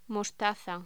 Locución: Mostaza
voz